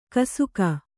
♪ kasuka